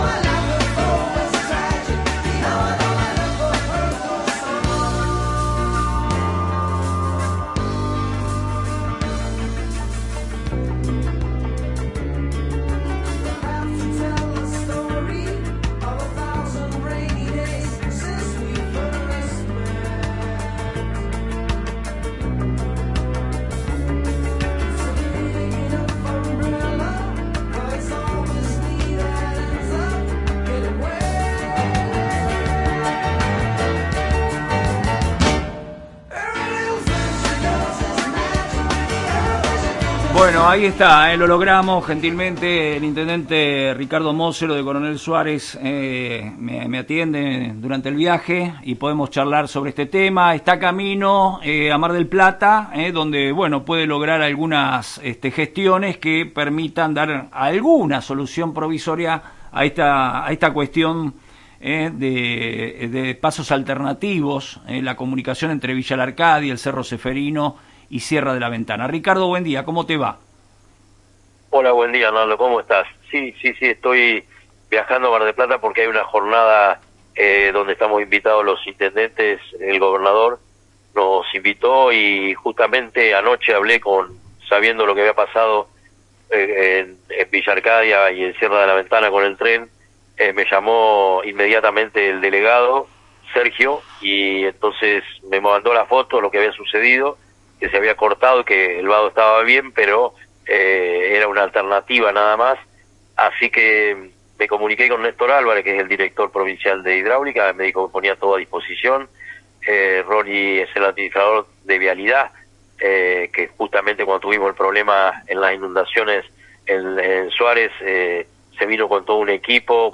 Ricardo Moccero hablo esta mañana en FM Reflejos se refirió al incidente y anunció que trabaja en conjunto con autoridades provinciales para encontrar una solución permanente»